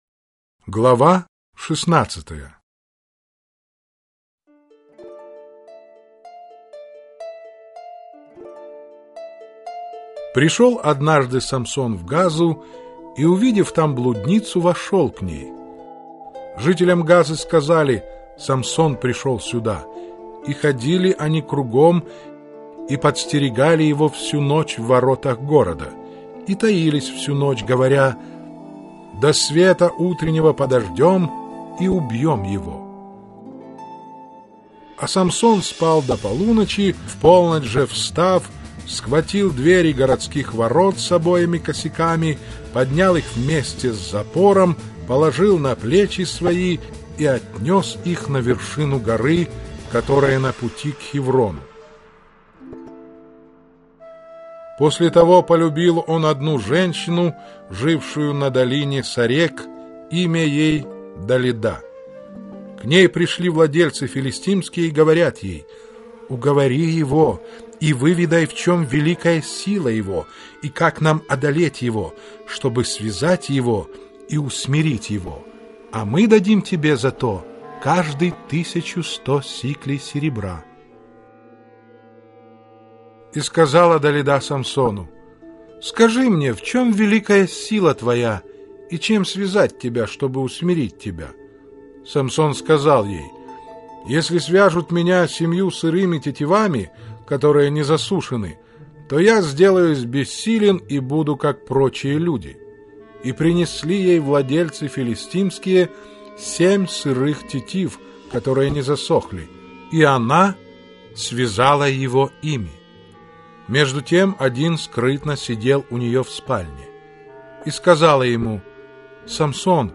Жанр: Аудиокнига
Чтение сопровождается оригинальной музыкой и стерео-эффектами